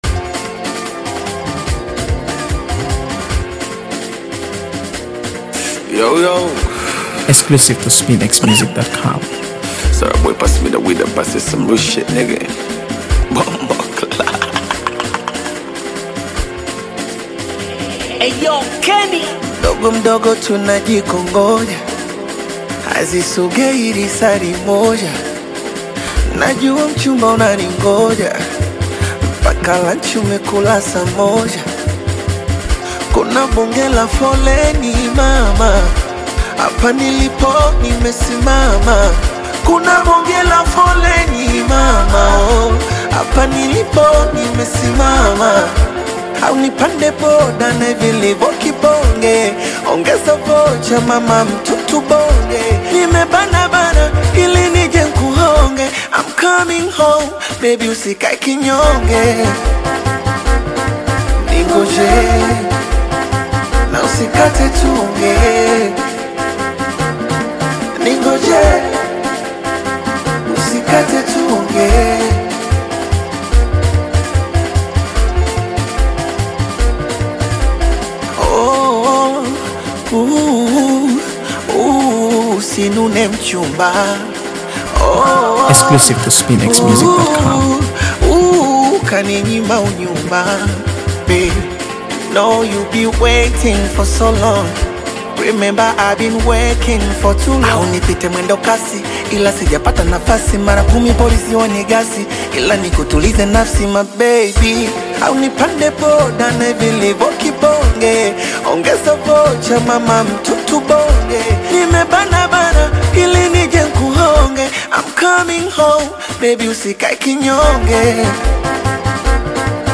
AfroBeats | AfroBeats songs
rich percussion and melodic rhythms
contemporary Afro sounds